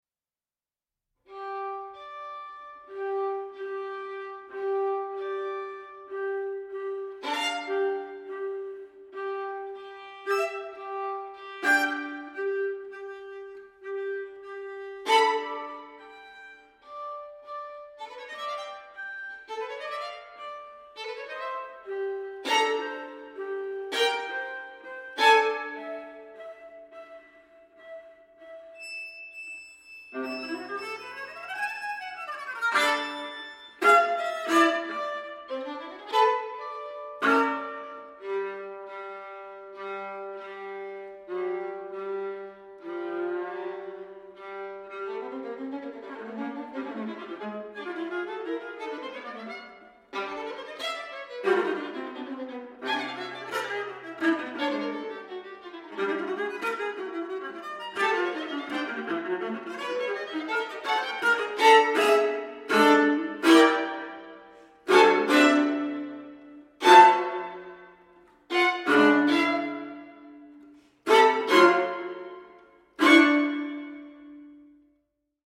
• Genres: Classical, Opera, Chamber Music
Recorded at Evelyn & Mo Ostin Music Center